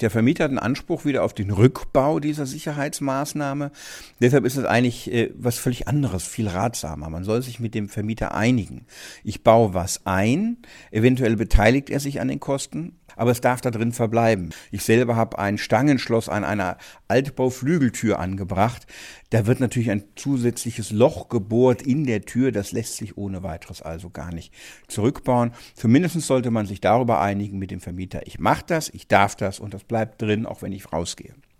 O-Ton: Sicherheitsschlösser müssen Mieter meist selber zahlen
DAV, O-Töne / Radiobeiträge, Ratgeber, Recht, , , , ,